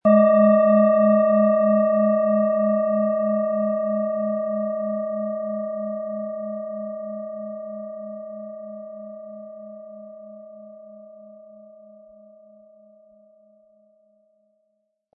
Planetenton 1
Wie klingt diese Planetenschale® Neptun?
SchalenformBihar
MaterialBronze